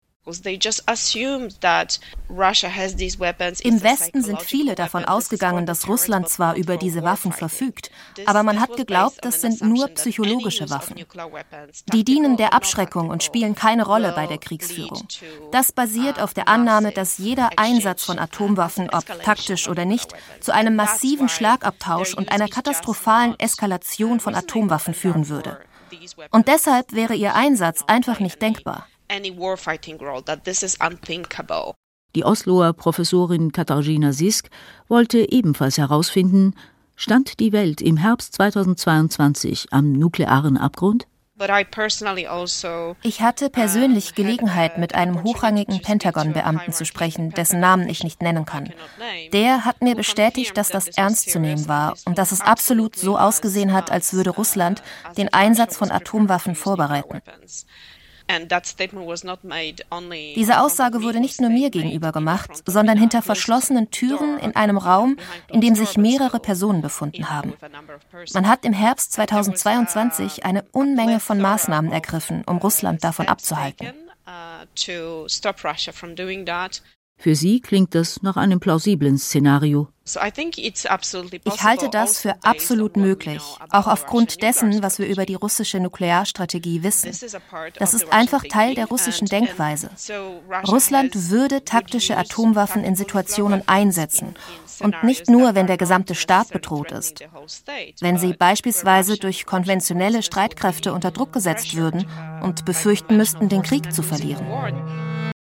Warm, klar, einfühlsam – meine Stimme zieht Zuhörer*innen in ihren Bann, vermittelt Emotionen und hinterlässt Eindrücke, die bleiben.
Im SWR Wissen Podcast war ich die deutsche Stimme einer ukrainischen Nuklear-expertin: